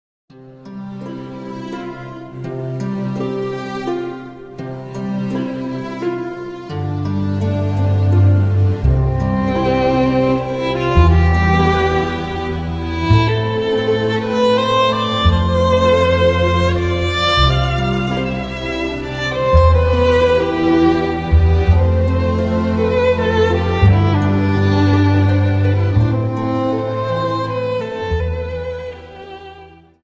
Dance: Slow Waltz Song